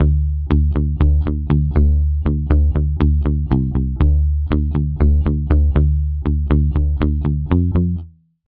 Standard Bassは無料で利用できるベース音源です。実際のベースを録音したサンプルが収録されており、打ち込みでも生演奏のような響きを得られます。